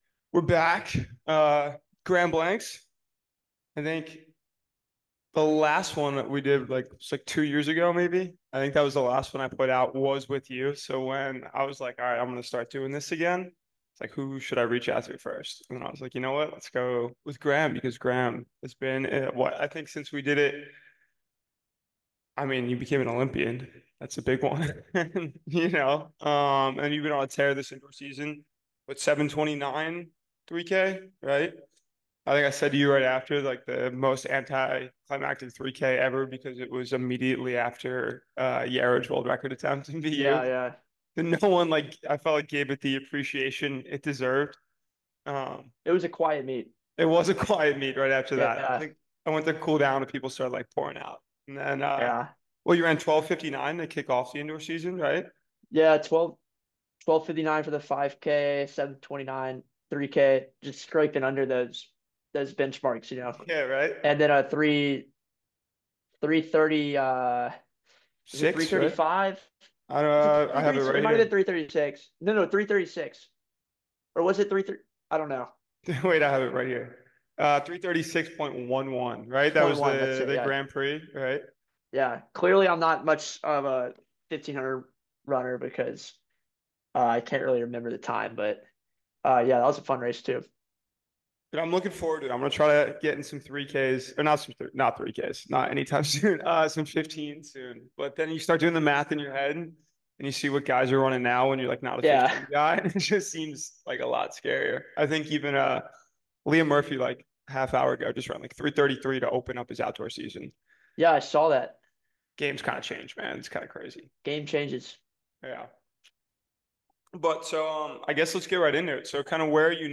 Tap in for a very insightful interview with a great mind in the sport.